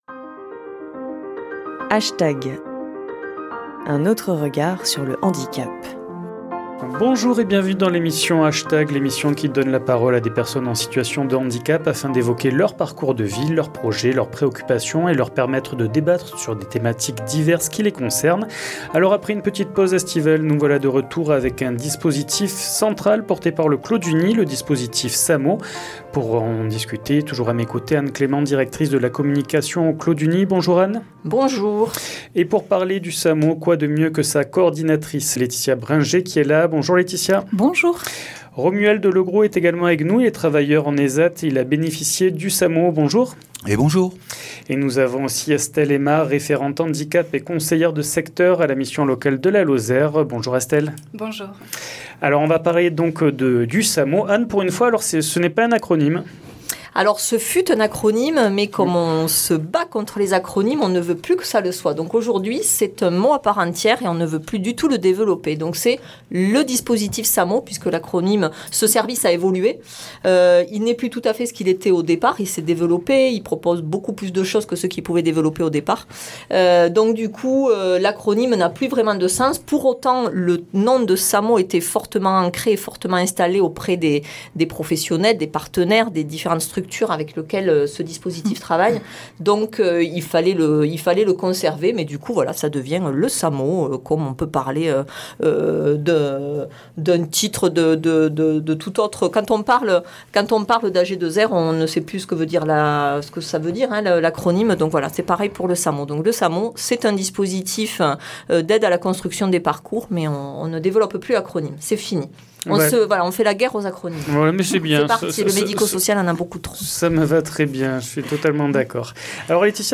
H Tag ! donne la parole à des personnes en situation de handicap ou des personnes en situation de vulnérabilité afin d’évoquer leurs parcours de vie, leurs projets, leurs préoccupations, et leur permettre de débattre sur des thématiques diverses qui les concerne